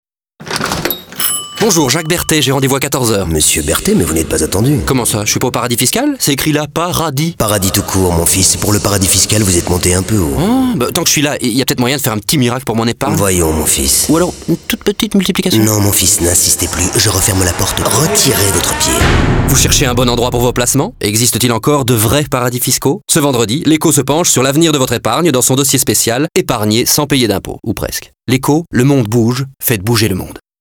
pub radio